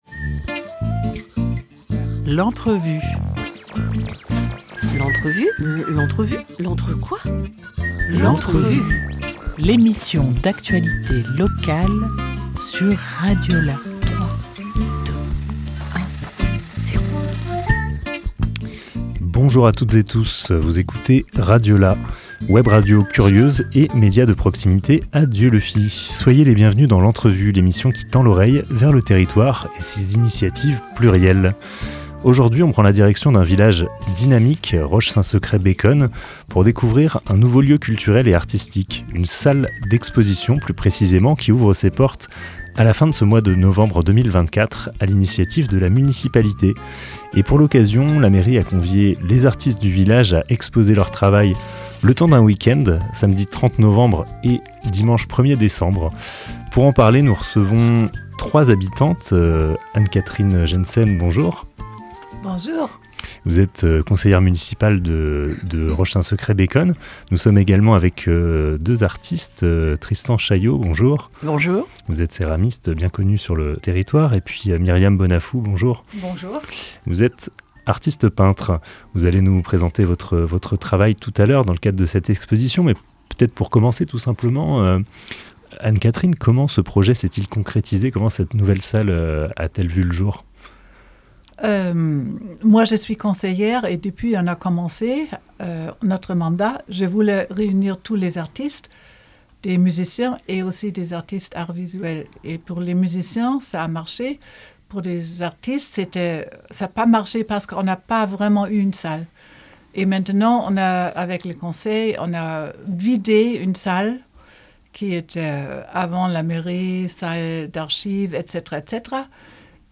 19 novembre 2024 14:40 | Interview